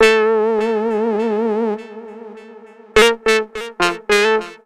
VOS SYNT 1-R.wav